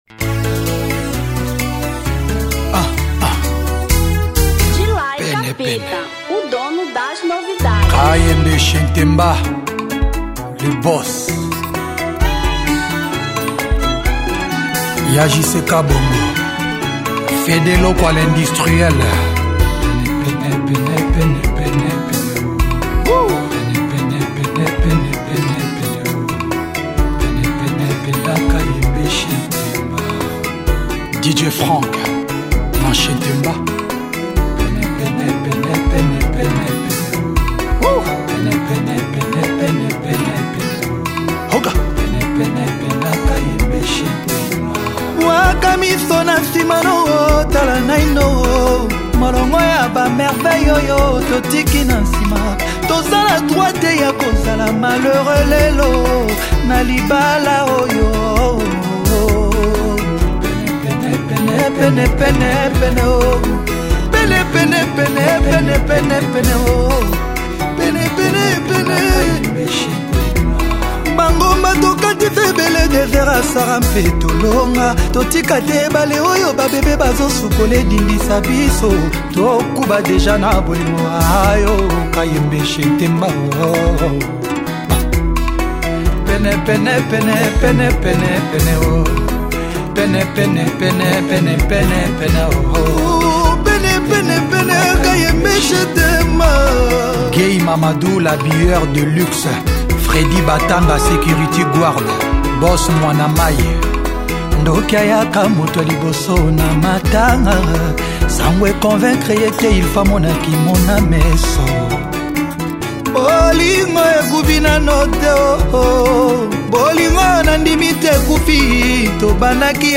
Rumba 2013